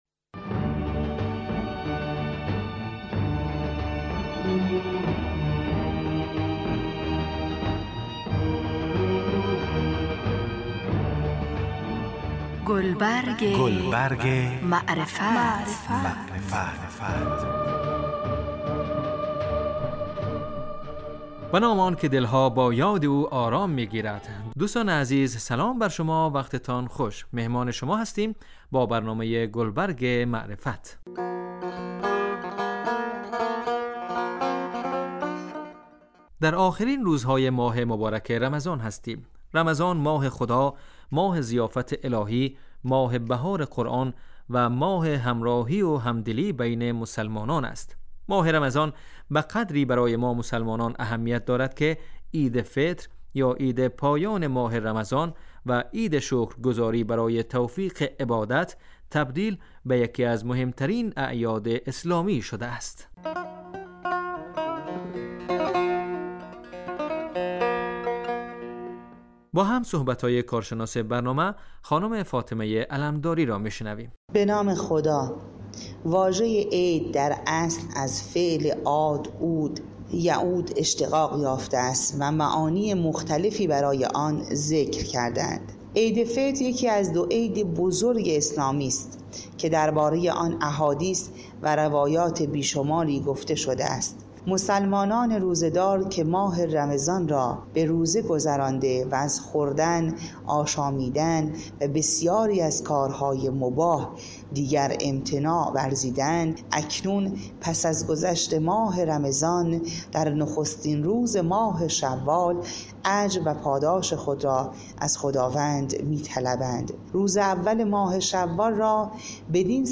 کارشناس